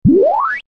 Ship2ship.wav